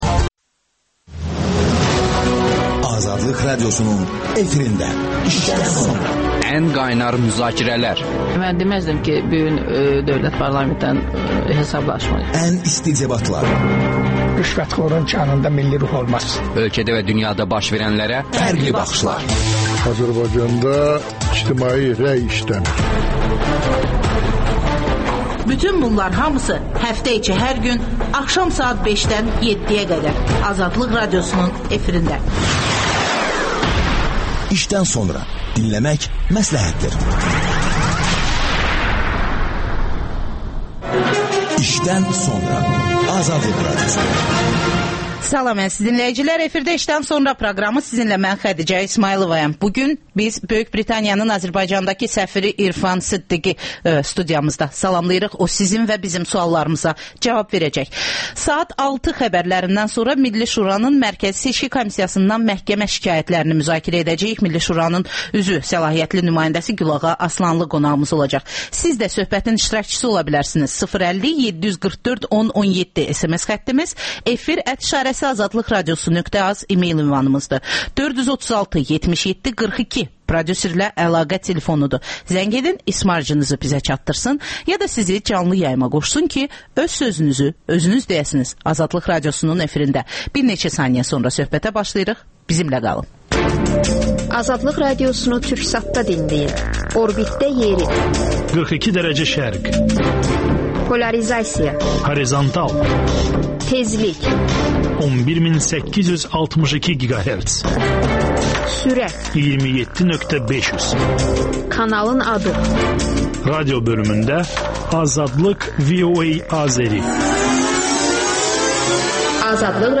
İşdən sonra - Böyük Britaniyanın Azərbaycandakı səfiri İrfan Sıddıq suallara cavab verir